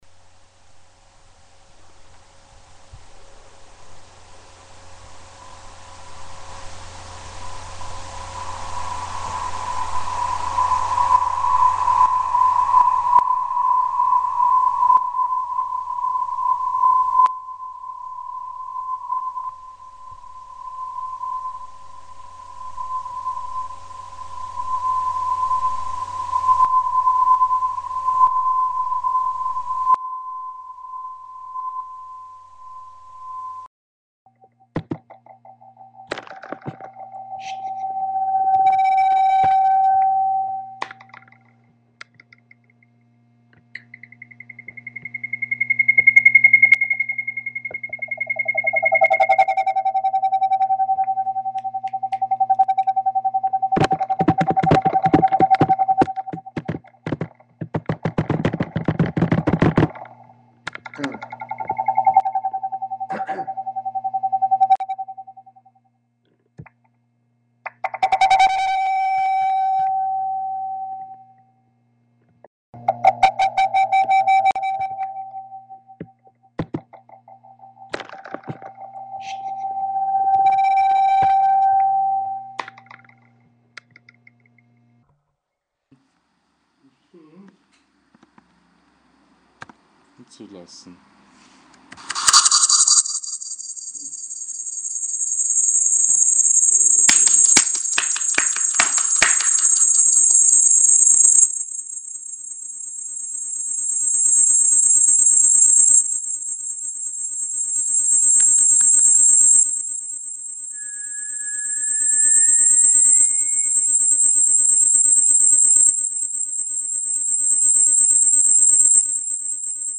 A website that explored the resonating space around computers visiting the site. Upon opening the site in the browser, it induced a controlled acoustic feedback loop which was recorded to a server.